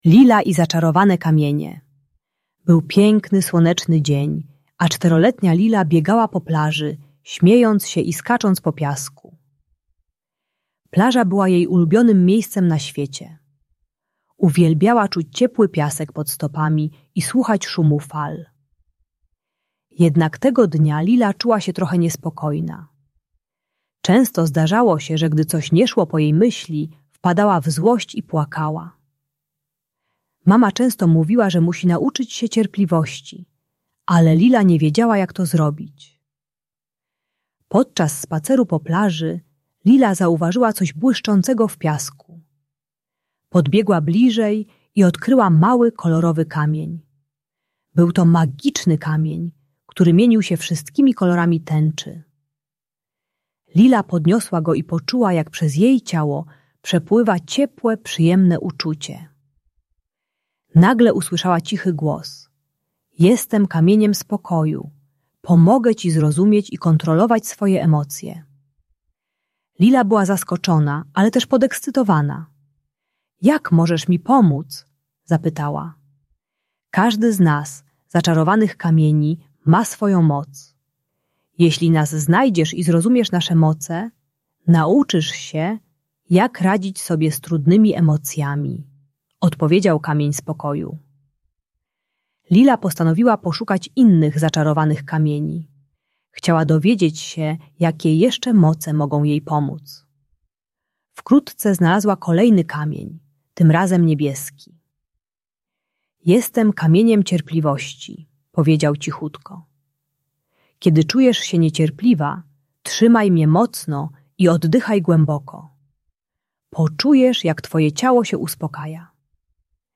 Lila i Zaczarowane Kamienie - Bunt i wybuchy złości | Audiobajka